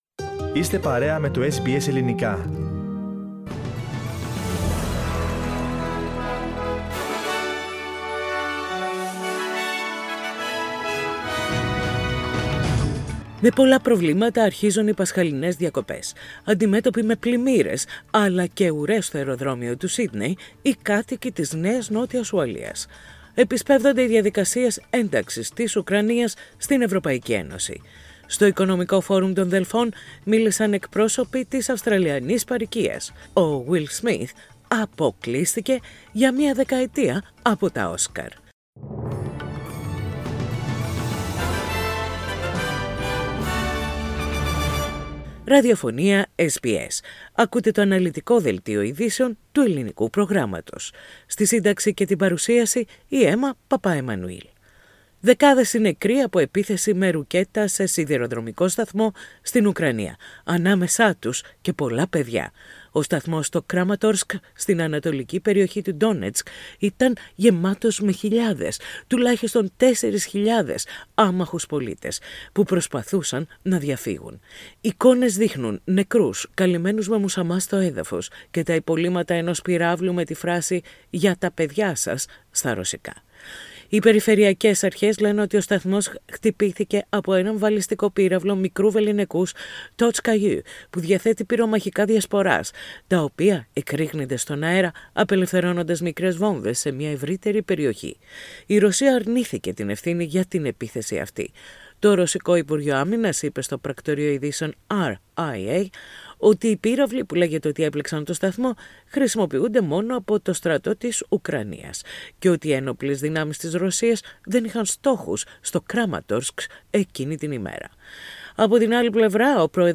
Δελτίο Ειδήσεων - Σάββατο 9.4.22
News in Greek. Source: SBS Radio